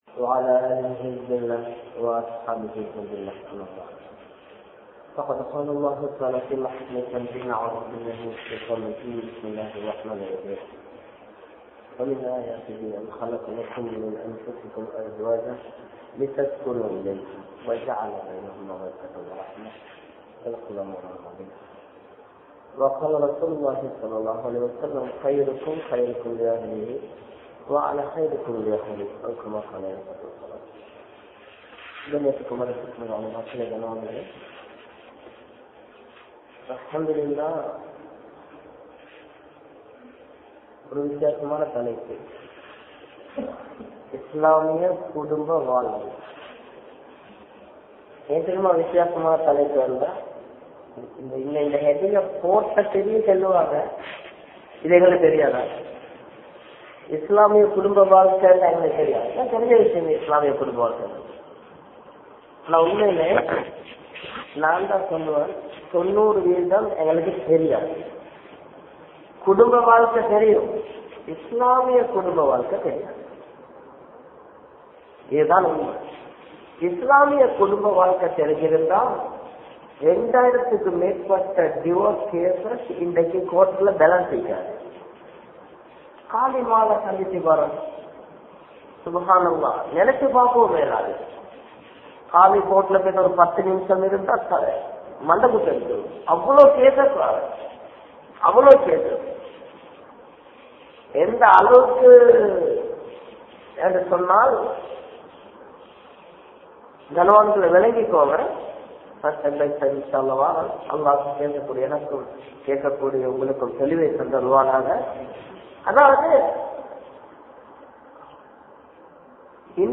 Islamiya Kudumba Vaalkai (இஸ்லாமிய குடும்ப வாழ்க்கை) | Audio Bayans | All Ceylon Muslim Youth Community | Addalaichenai